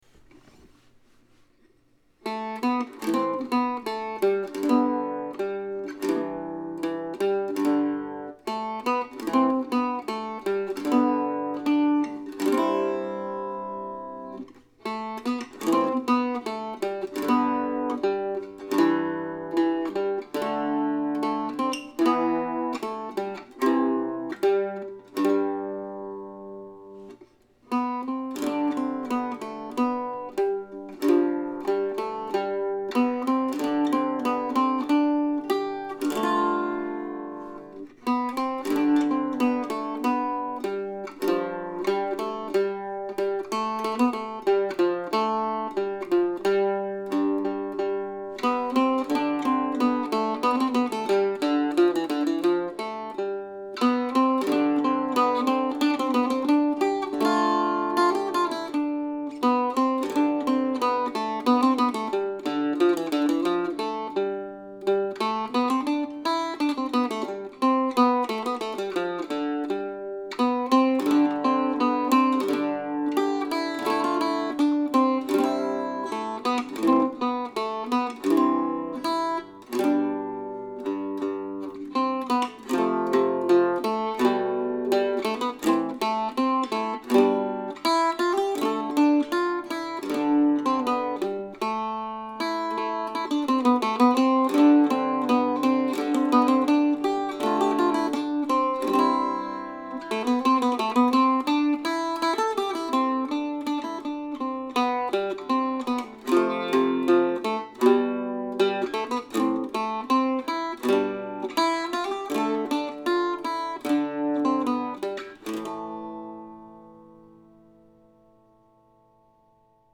Summer Suite, August, 2020 (for Octave Mandolin or Mandocello)
So I took five of my favorite sections from the Postcards and played around with them on my octave mandolin.
I've set it here in a different key with quite a few double stops and drones and even some brief division-like variations. It doesn't need to go too fast.